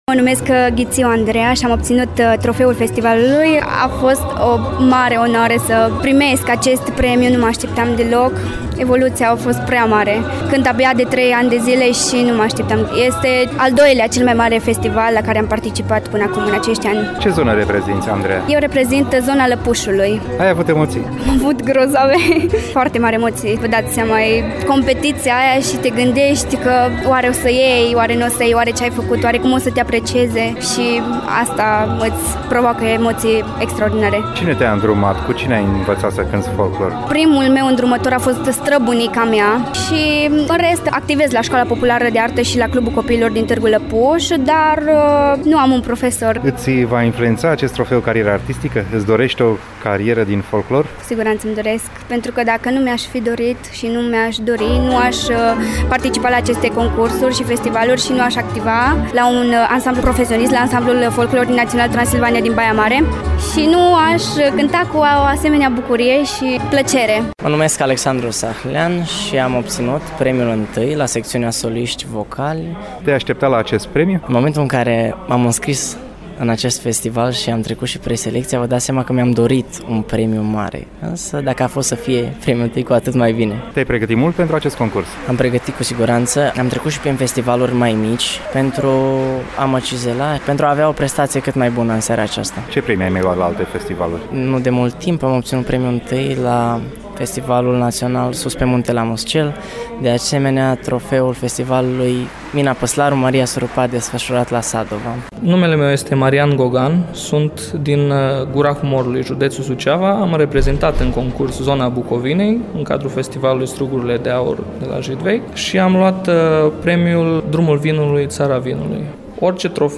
reportajJidvei-ptr-site.mp3